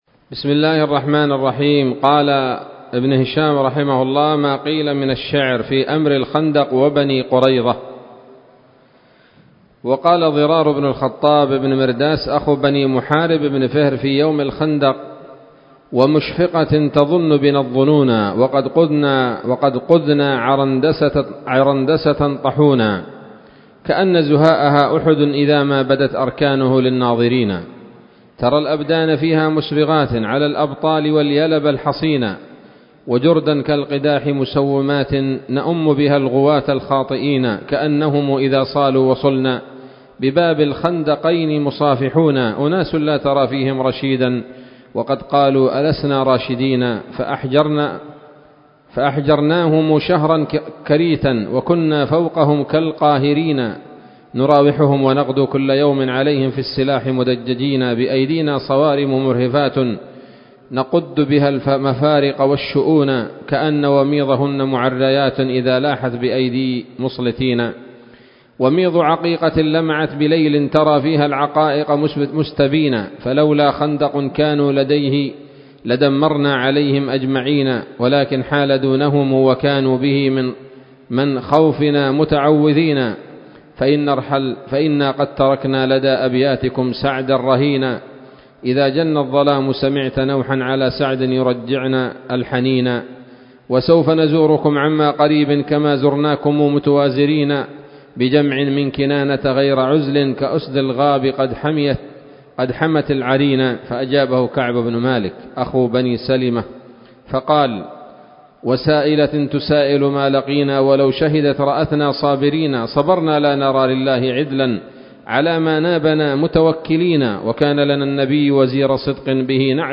الدرس الرابع عشر بعد المائتين من التعليق على كتاب السيرة النبوية لابن هشام